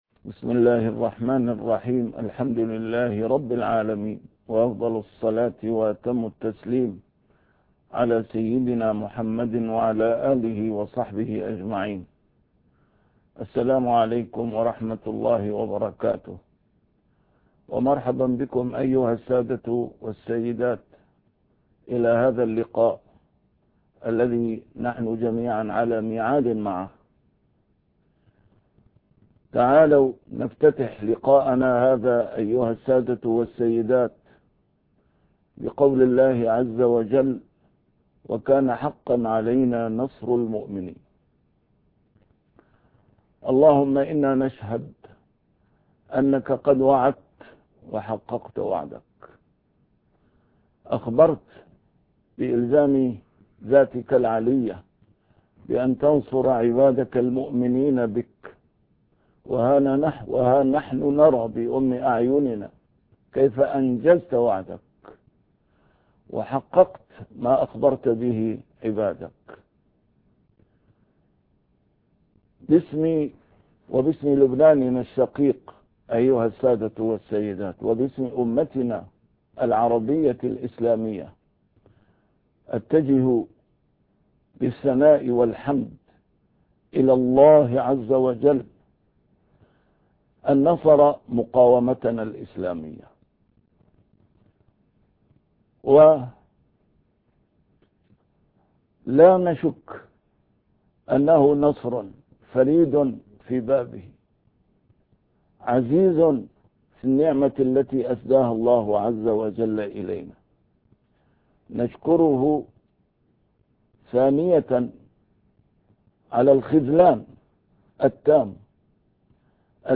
A MARTYR SCHOLAR: IMAM MUHAMMAD SAEED RAMADAN AL-BOUTI - الدروس العلمية - درسات قرآنية الجزء الثاني - 4- الحرب على الإسلام